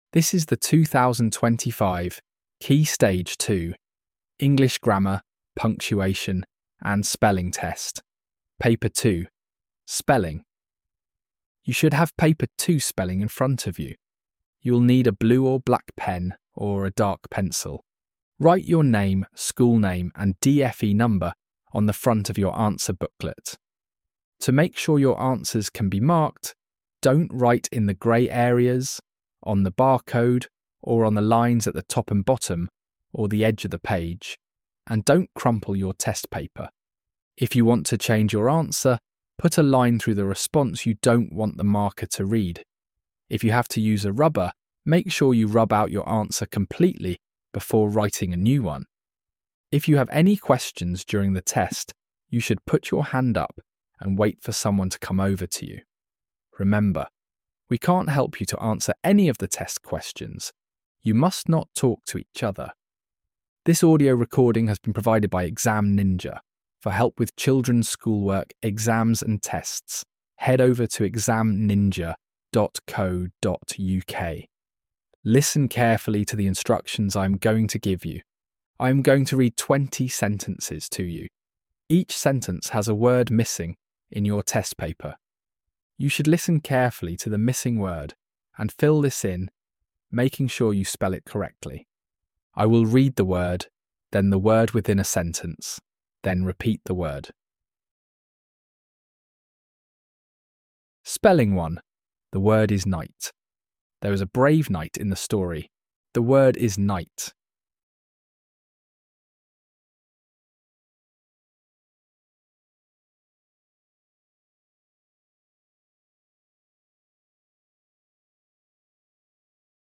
Paper 2 focuses specifically on spelling skills through a structured test format. Students complete 20 fill-in-the-blank sentences where words are read aloud by the teacher.